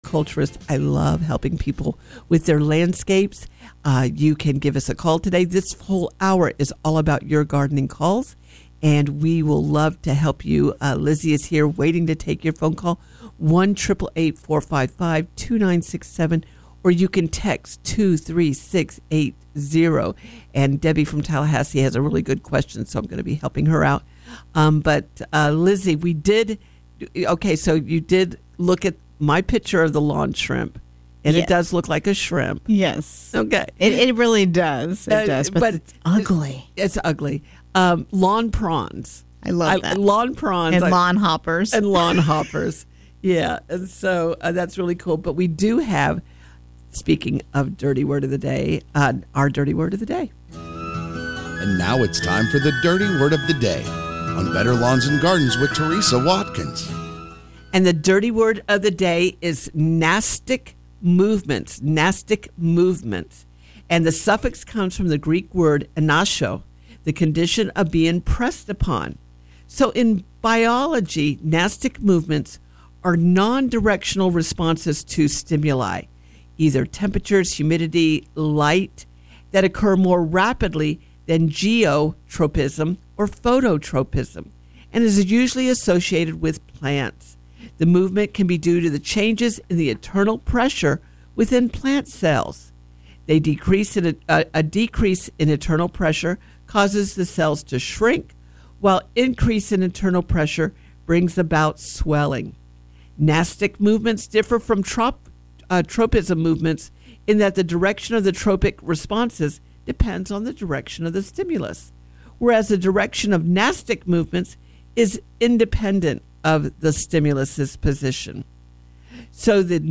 Better Lawns and Gardens Hour 2 – Coming to you from the Summit Responsible Solutions Studios.